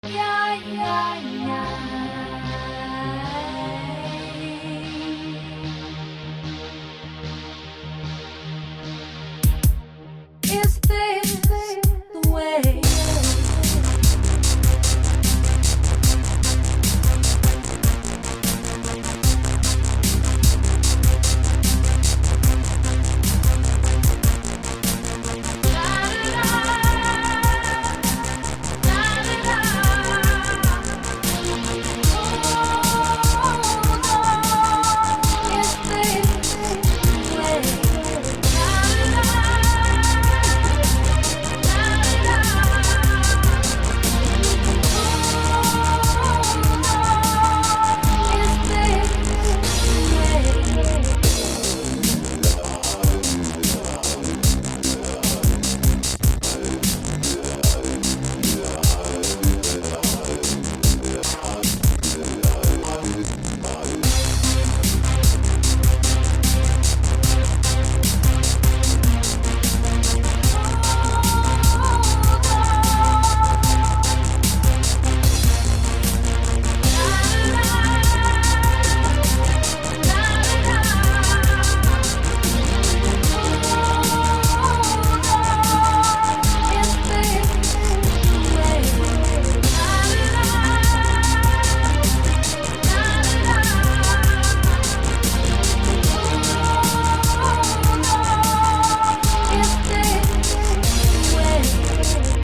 Pop-Rock-Song